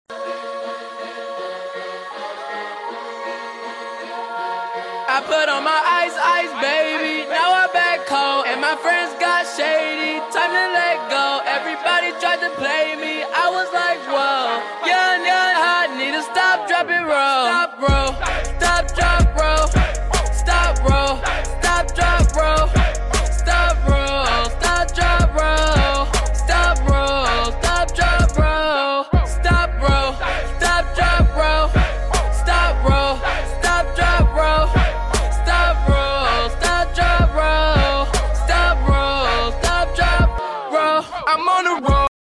Genres: Hip Hop, Trap, Cloud Rap